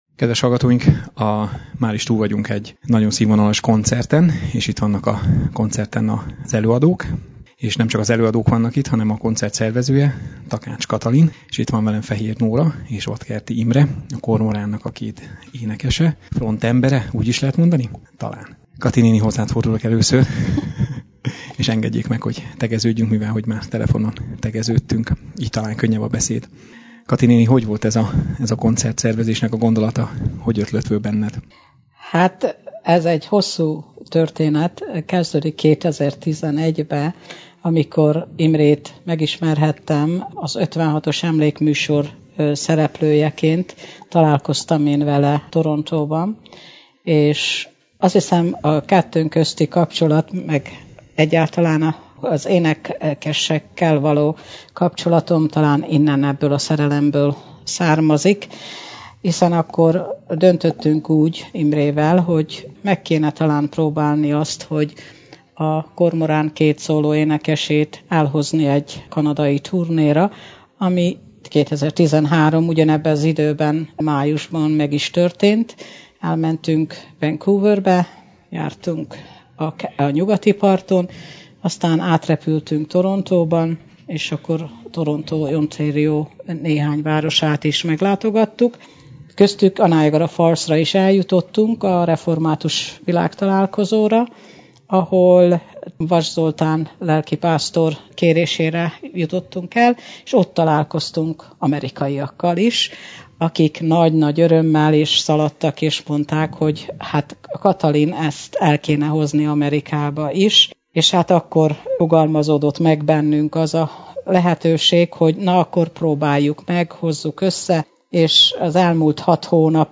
A koncert végén a táncház egyik rövid szünetében leültem velük egy rövid interjú erejéig.